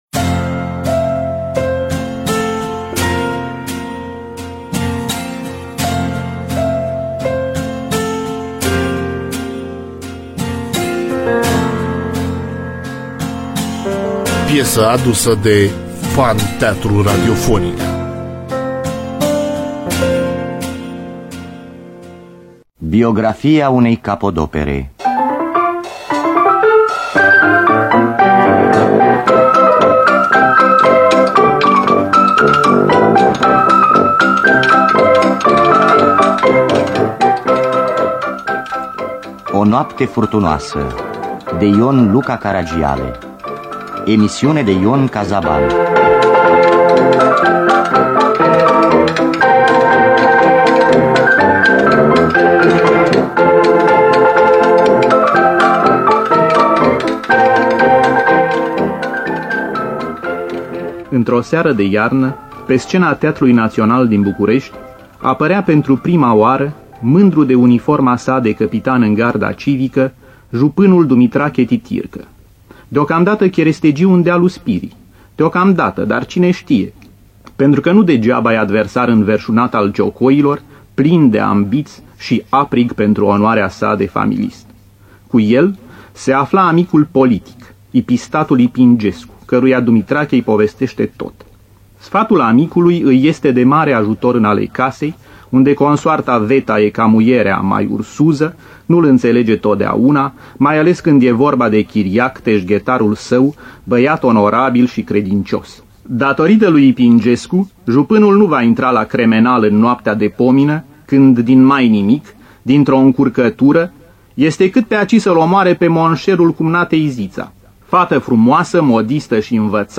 Fragmente din piesa O noapte furtunoasă de I.L. Caragiale. Înregistrare din Fonoteca de aur a Teatrului Național Radiofonic.